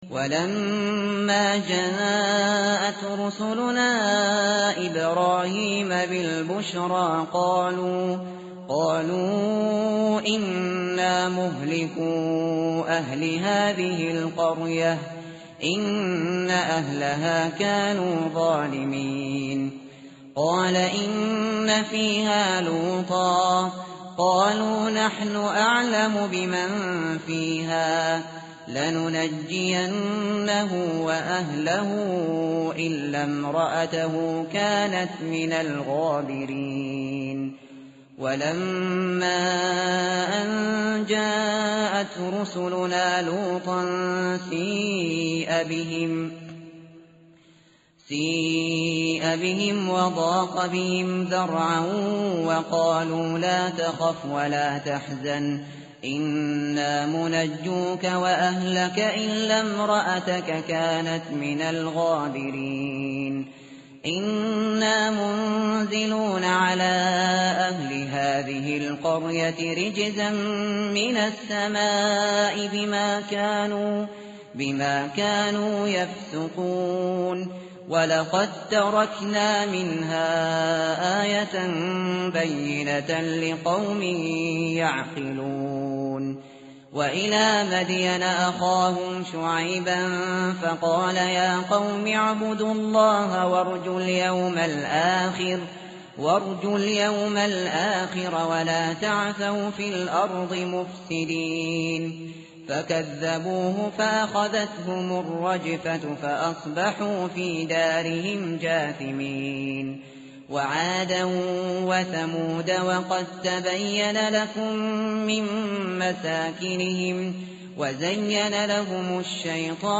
tartil_shateri_page_400.mp3